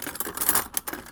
R - Foley 201.wav